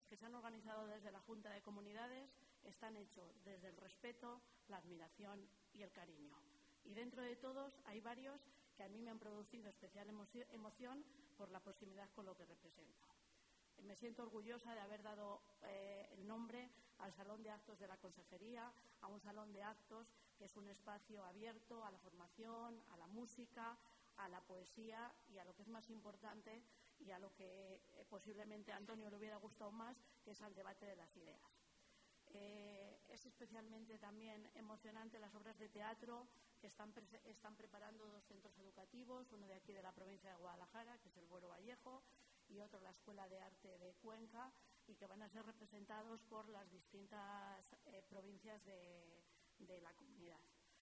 La viceconsejera de Educación habla sobre la sala de arte Buero Vallejo en la Delegación de la Junta de Guadalajara